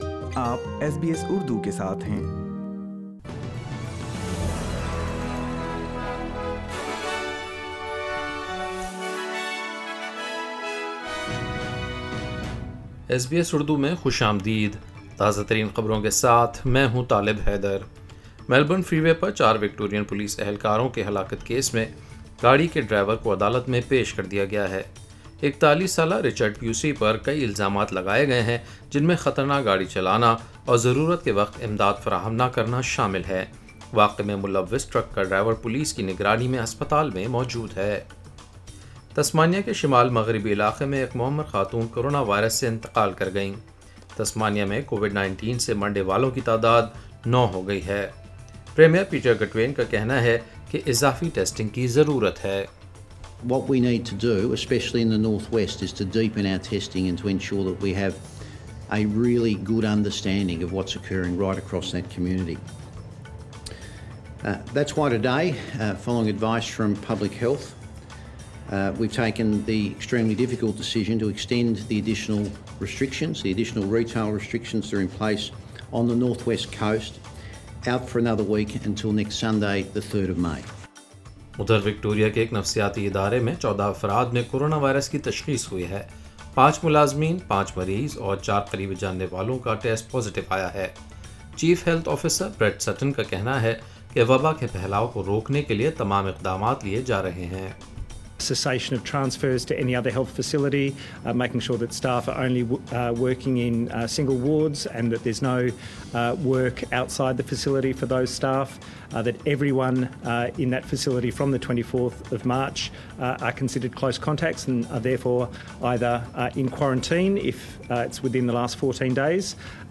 Listen to the latest Australian news on SBS Urdu.